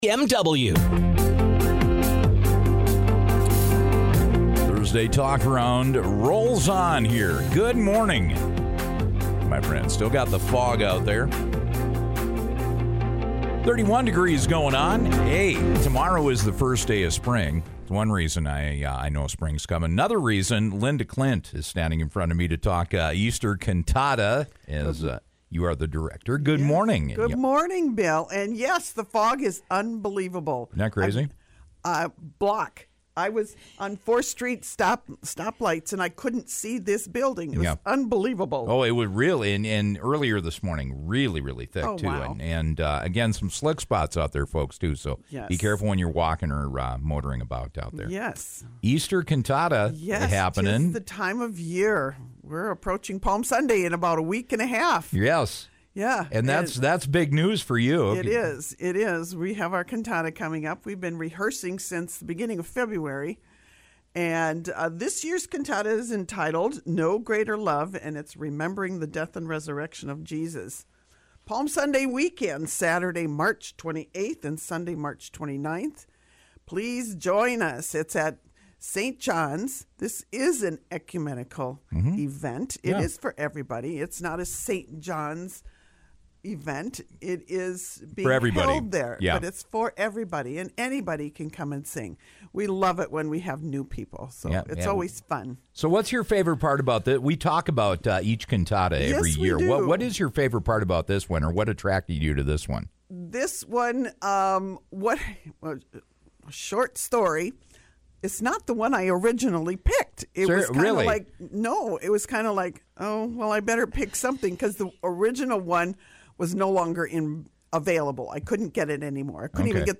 The Community Choir comes together to celebrate the Easter Season on Saturday March 28th and Sunday March 29th at St. John’s Church.
cantata.mp3